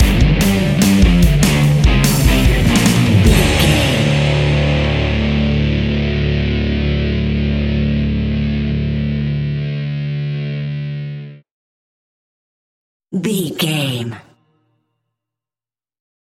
Powerful Rock Music Cue Stinger.
Fast paced
Aeolian/Minor
distortion
instrumentals
rock guitars
Rock Bass
heavy drums
distorted guitars
hammond organ